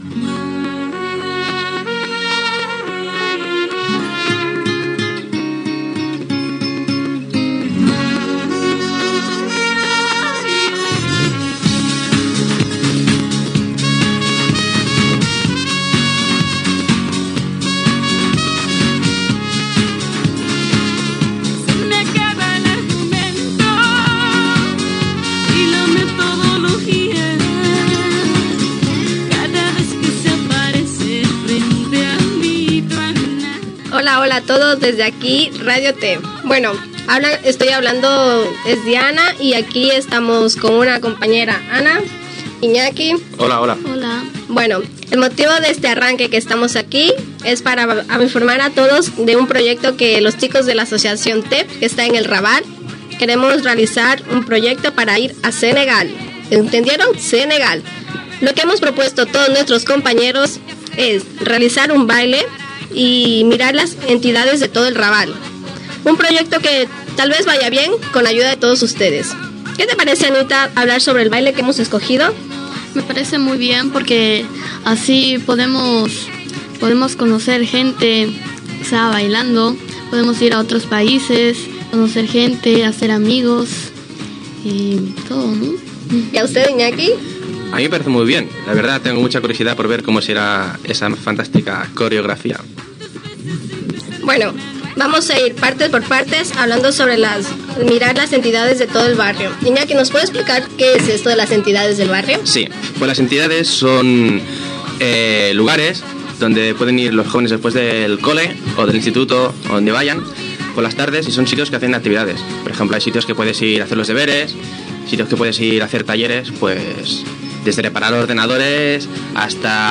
Infantil-juvenil